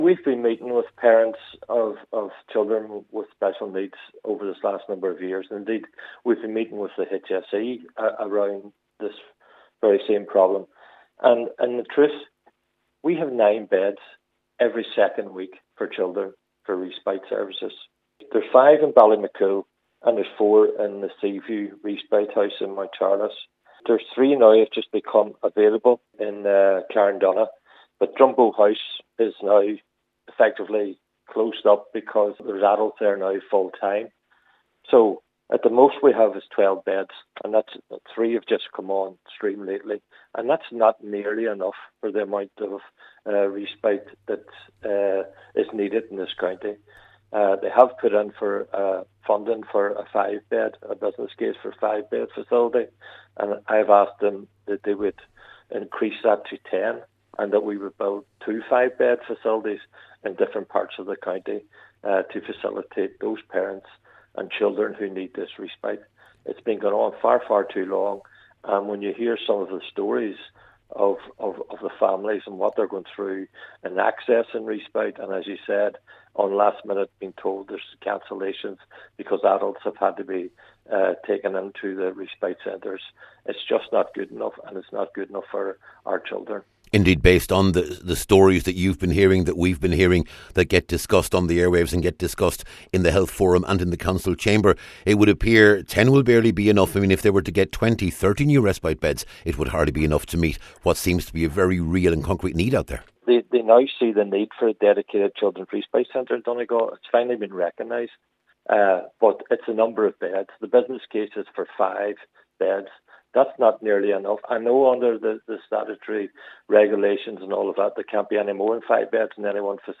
Cllr McMonagle says the reality is at present, there are 12 beds available every second week for child respite services: